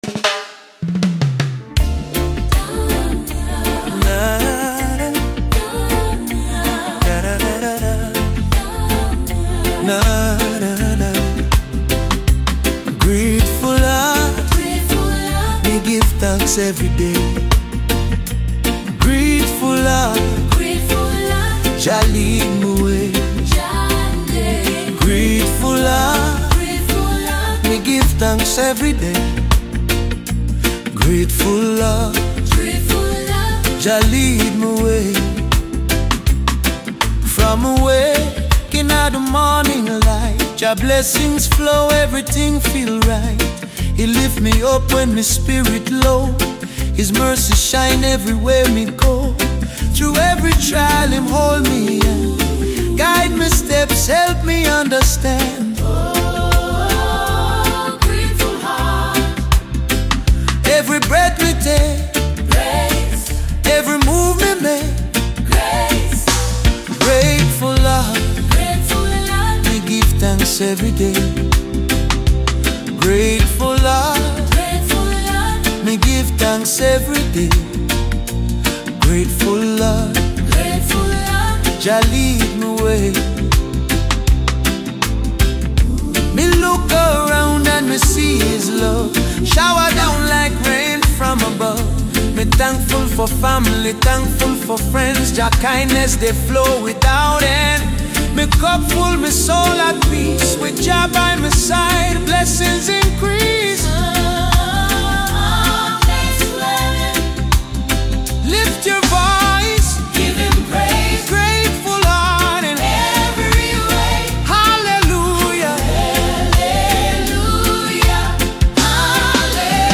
The emotion feels natural, not forced.